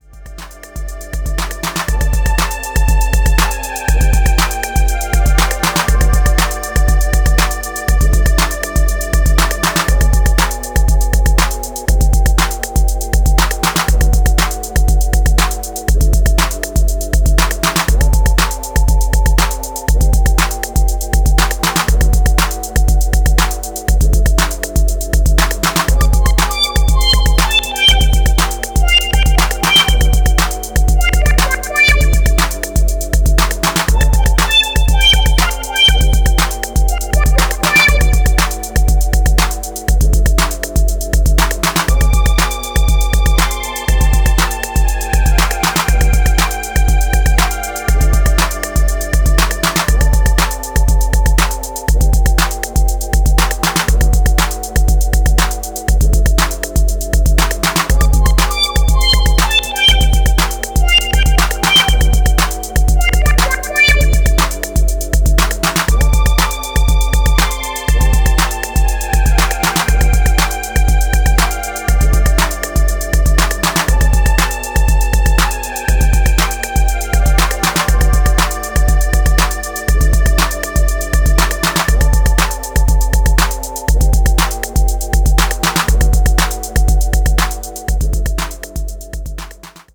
艶やかなマシン・ビートとスペーシー・シンセが絡むエレクトロ・ファンク